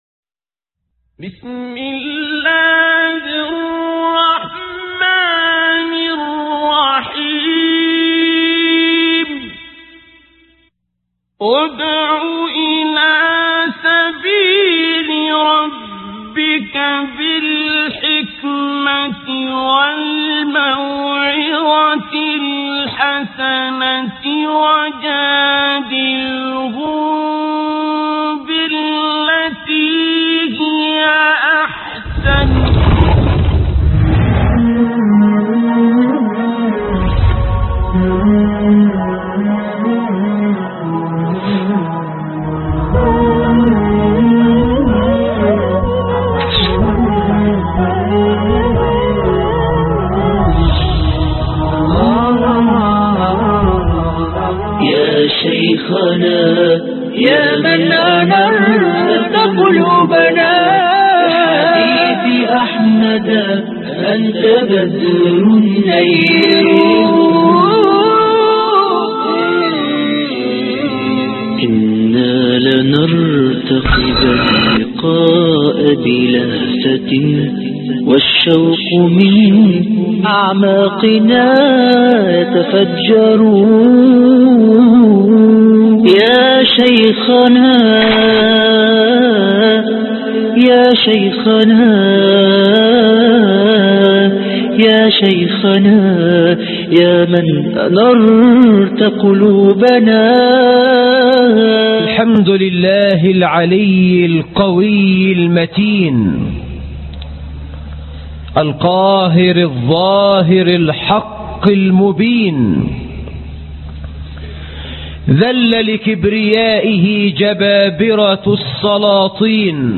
عجباً لأمر المؤمن إن أمره كله له خير - خطب الجمعة